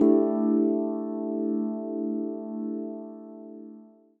Rhodes Steveland 2.wav